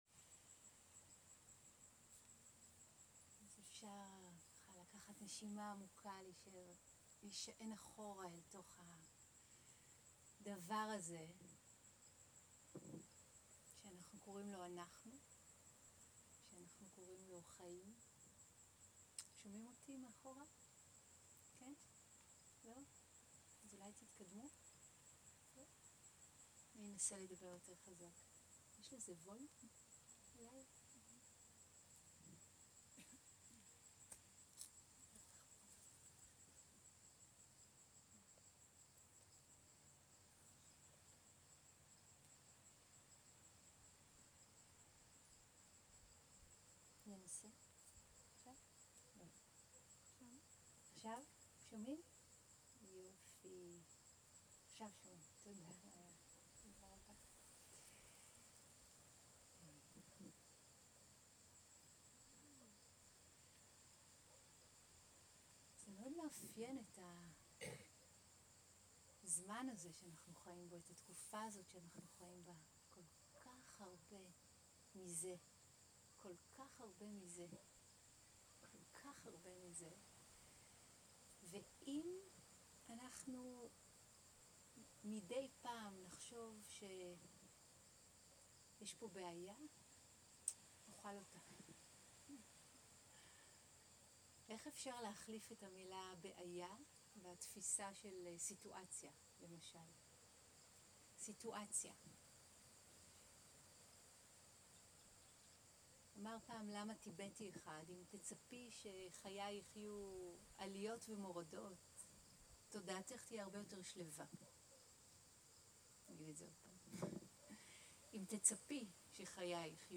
Dharma type: Dharma Talks שפת ההקלטה